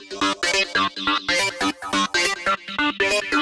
Index of /musicradar/future-rave-samples/140bpm
FR_RaveSquirrel_140-G.wav